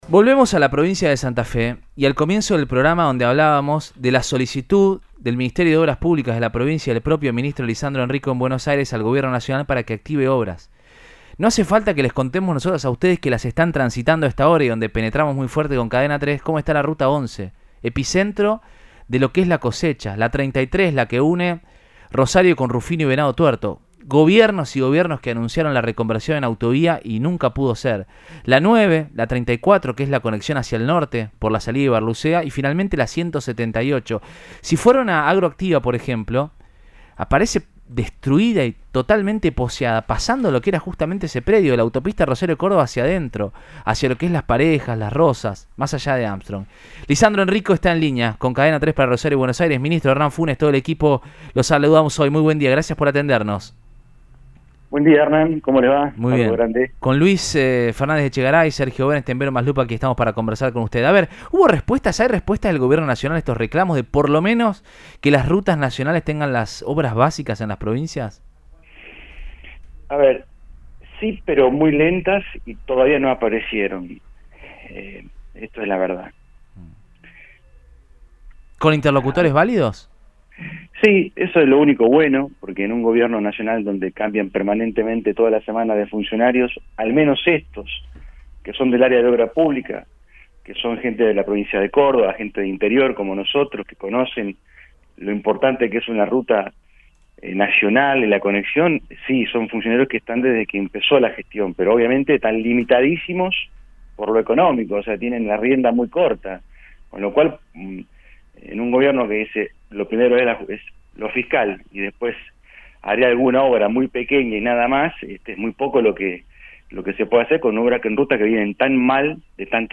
El Ministro de Obras Públicas de Santa Fe, Lisandro Enrico, fue entrevistado
en la radio Cadena 3 Rosario
14-06-RI3-LISANDRO-ENRICO-MIN-DE-OBRAS-PUBLICAS-STA-FE.mp3